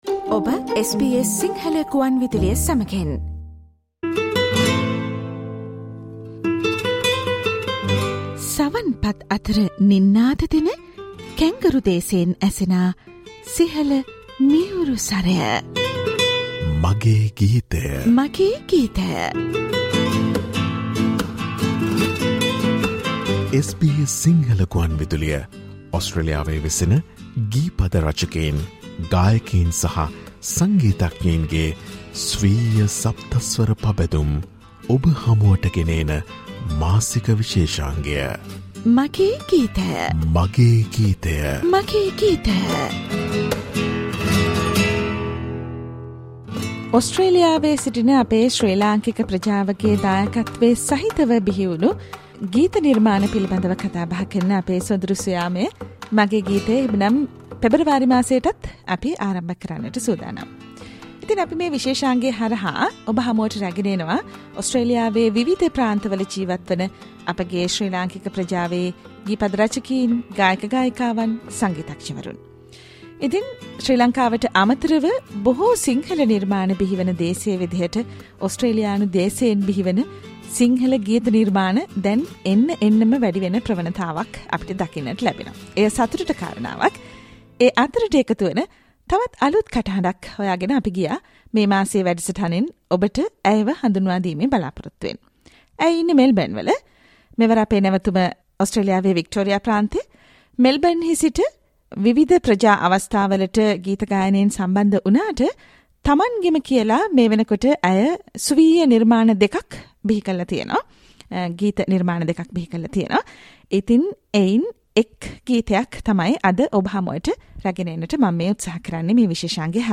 ගායනය